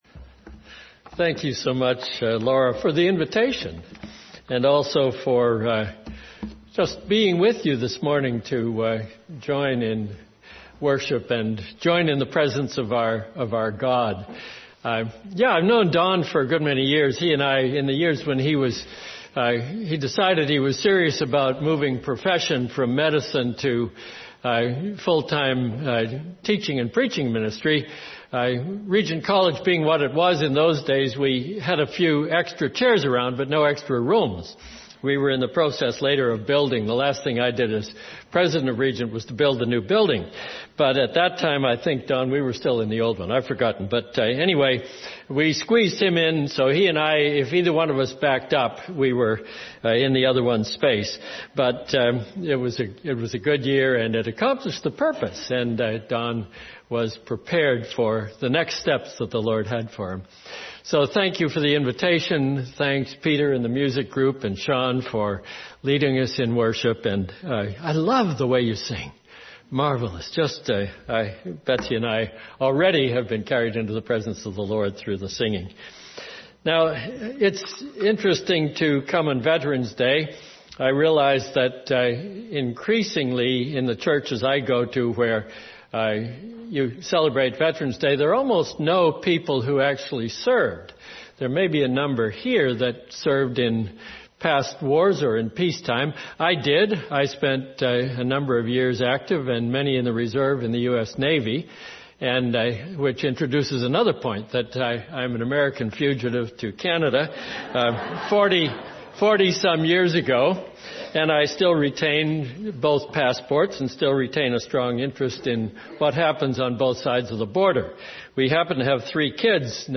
Sermons | Olivet Baptist Church
Guest Speaker